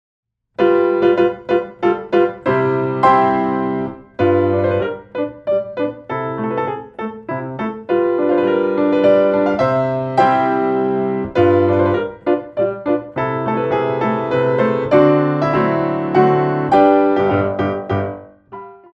2 bar intro 3/4
32 bars